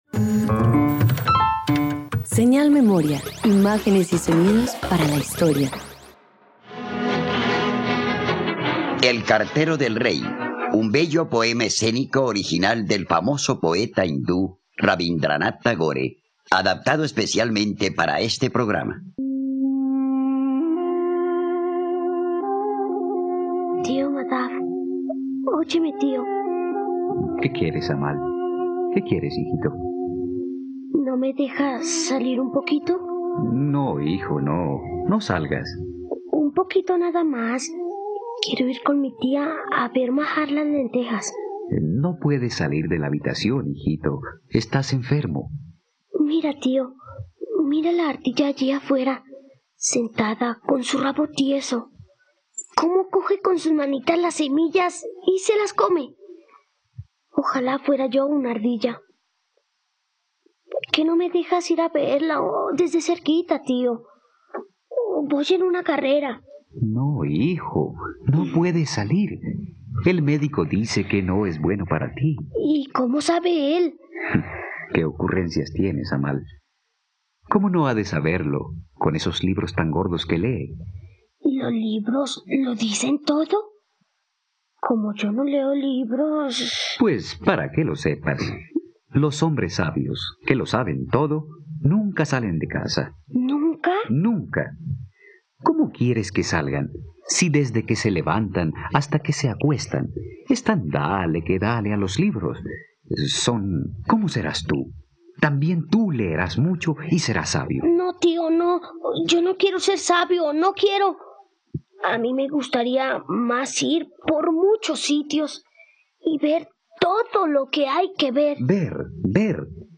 El cartero del rey - Radioteatro dominical | RTVCPlay